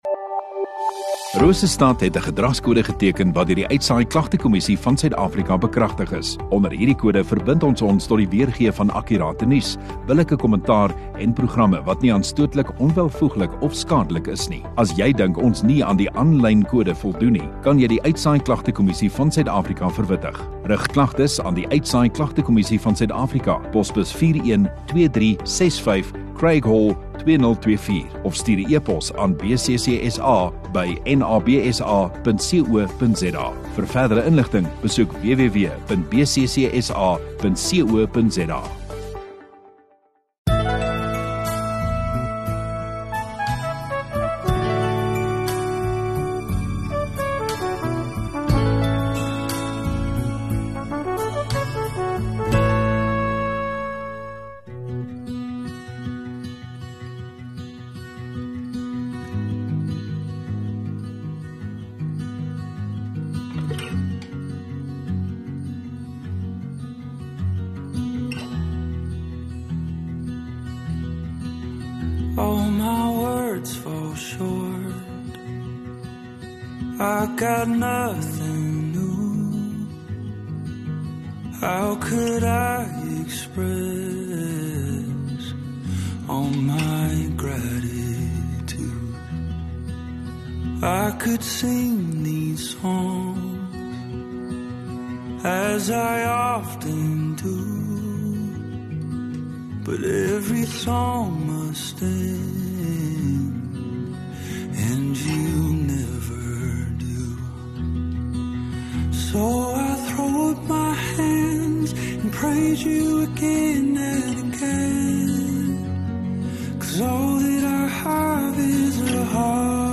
14 Dec Saterdag Oggenddiens